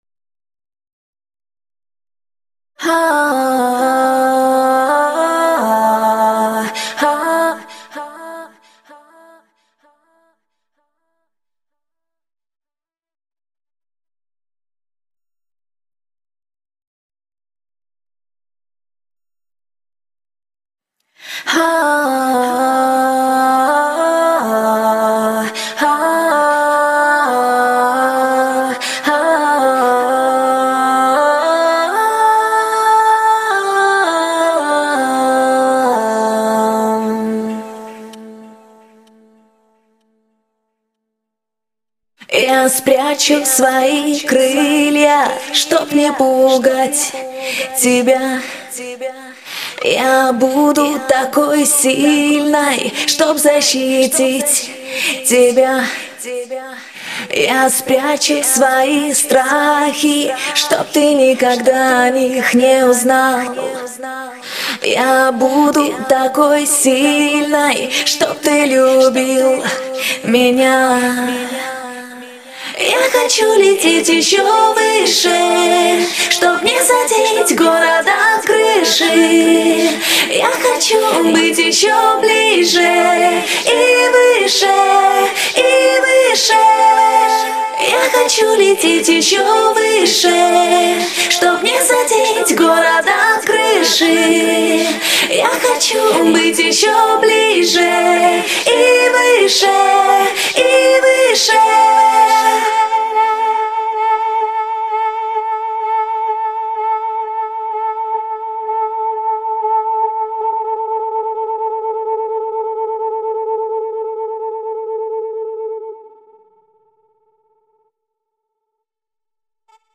а нахрена реверб делать?